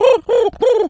pigeon_call_sequence_04.wav